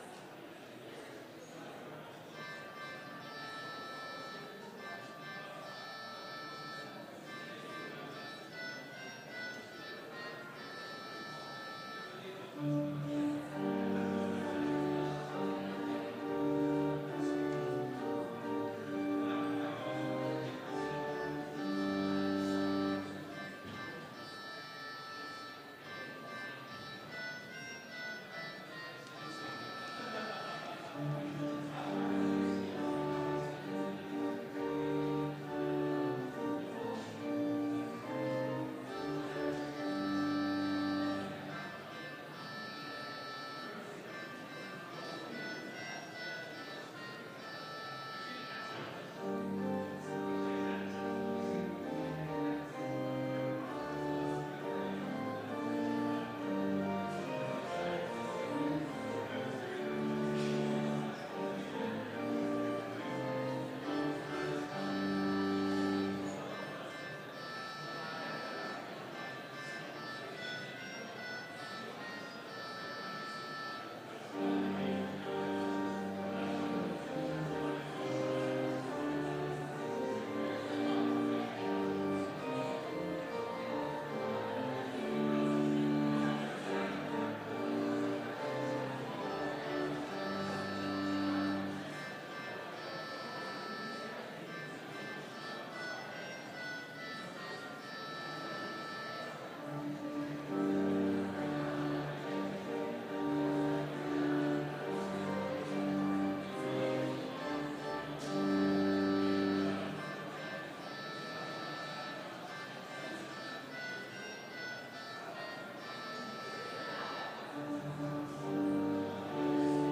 Complete service audio for Chapel - November 18, 2019